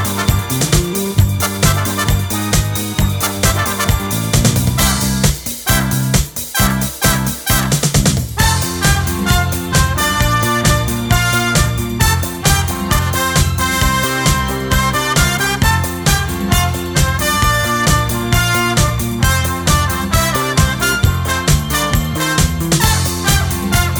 no Backing Vocals Disco 3:23 Buy £1.50